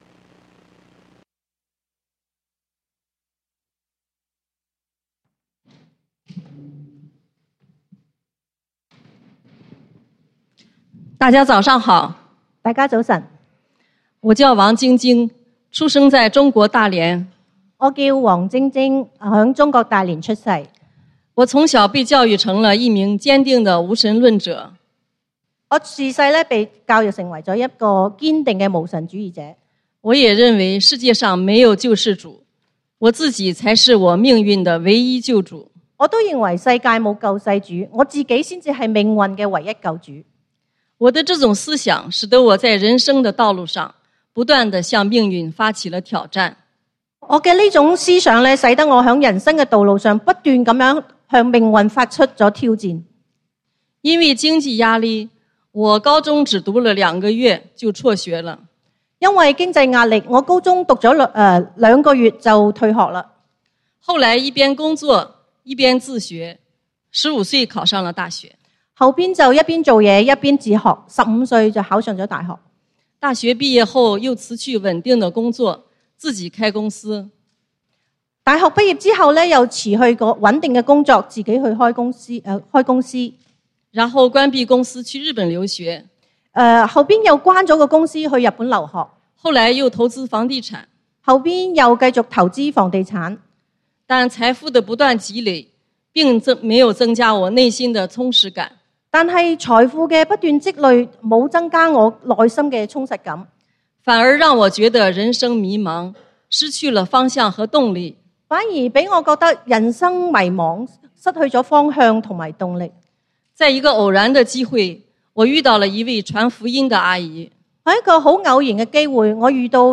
見證分享